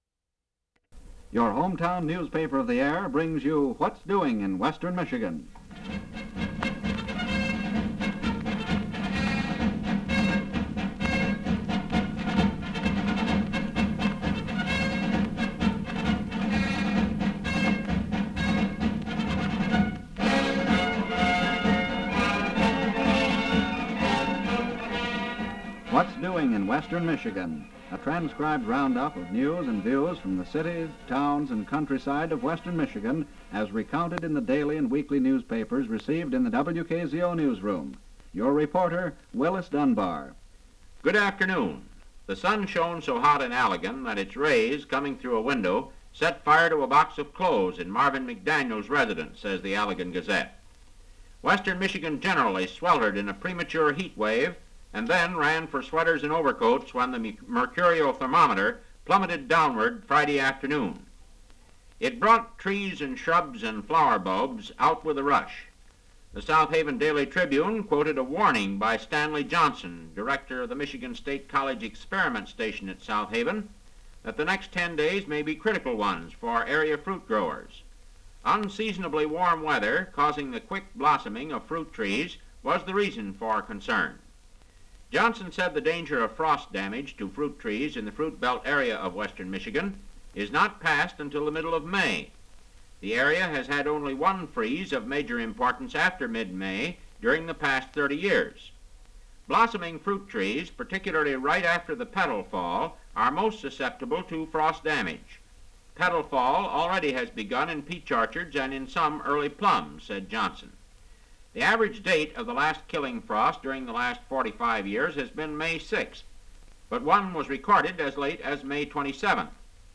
Radio programs
Broadcast 1949 May 8